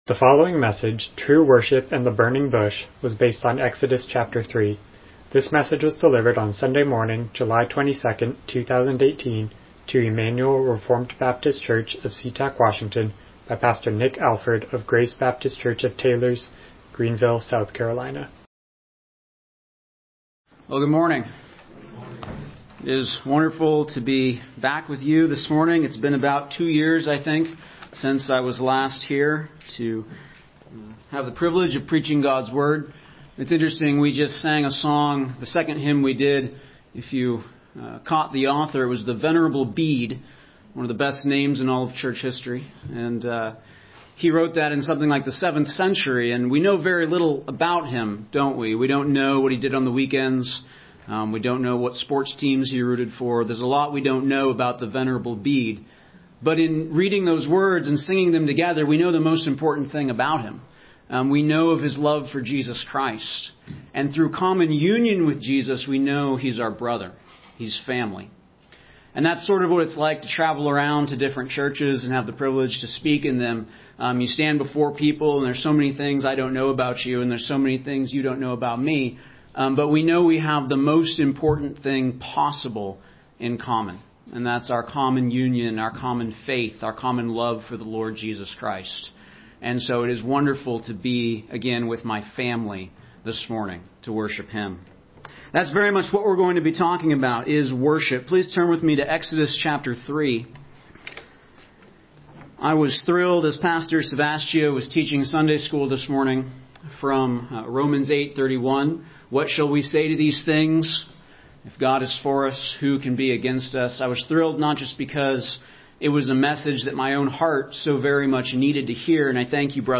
Exodus 3:1-22 Service Type: Morning Worship « What Shall We Say to These Things?